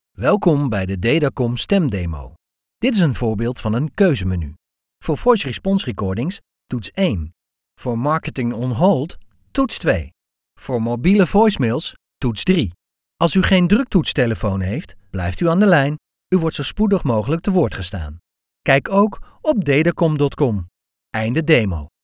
Beluister hier enkele voorbeelden van onze professionele voice over stemartiesten.